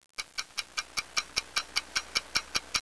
Stopwatch
Stopwatch.wav